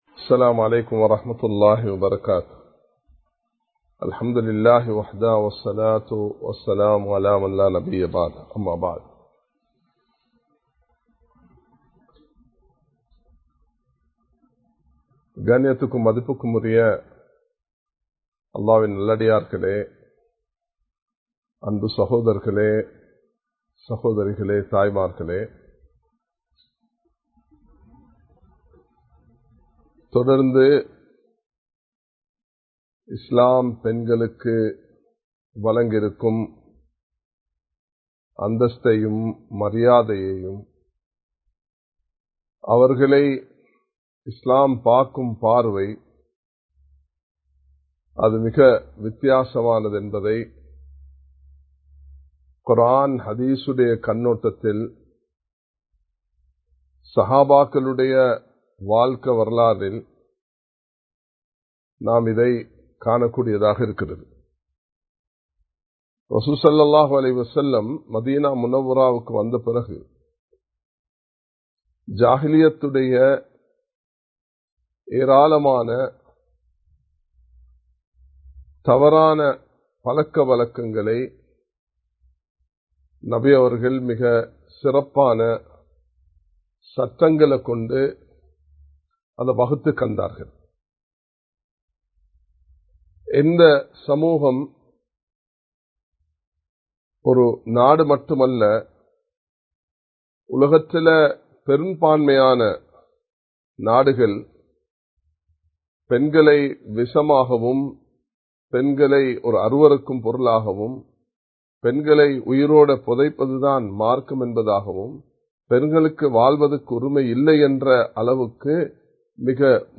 இஸ்லாமிய பார்வையில் பெண்கள் (பகுதி 02) | Audio Bayans | All Ceylon Muslim Youth Community | Addalaichenai
Live Stream